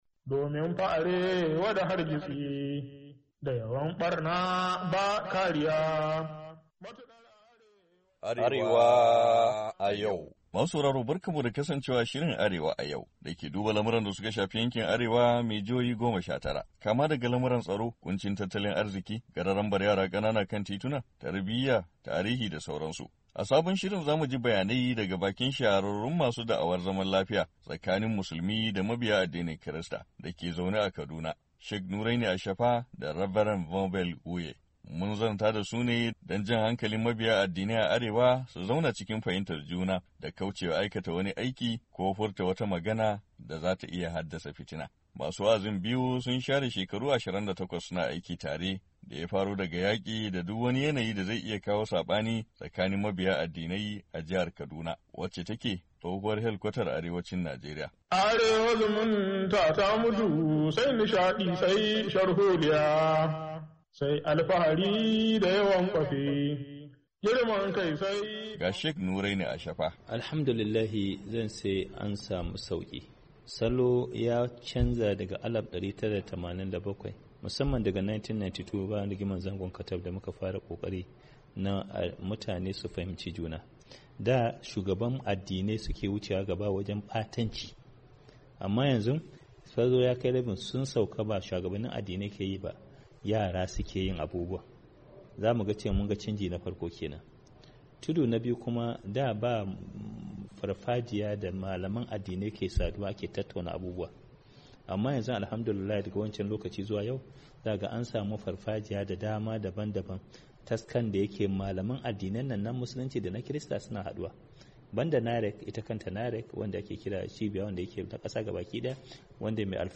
A cikin shirin na wannan makon mun gayyato malaman addini ne guda biyu da su ka shahara kan fannin karfafa fahimtar juna tsakanin Musulmai da Kirista daga cibiyar su a Kaduna.